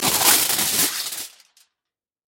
Звуки фольги - скачать и слушать онлайн бесплатно в mp3